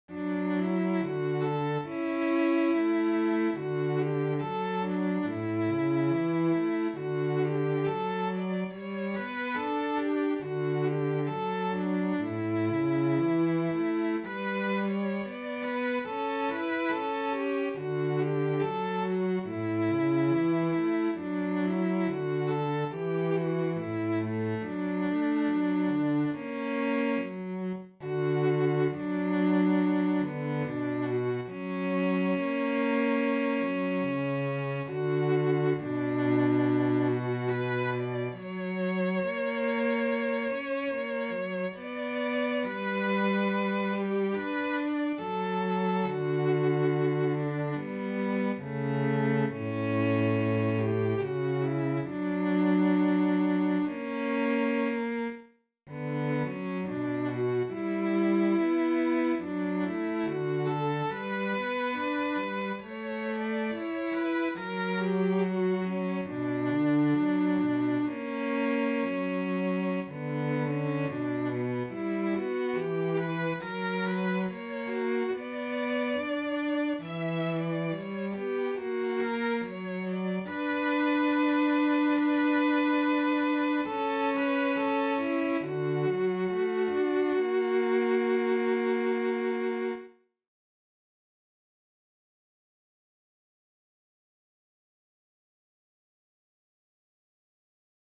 KUvaamatonta-sellot.mp3